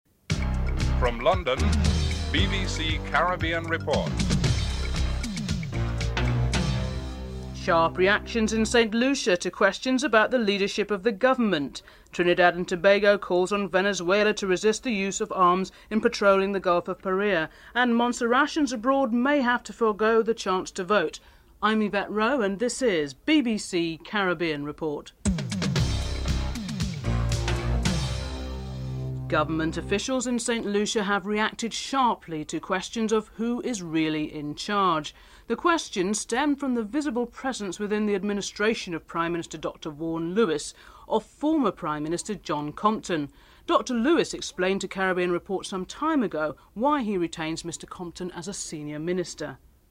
1. Headlines (00:00-00:28)
3. Trinidad and Tobago calls on Venezuela to resist the use of arms in patrolling the Gulf of Paria.